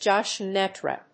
アクセント・音節jób・cèntre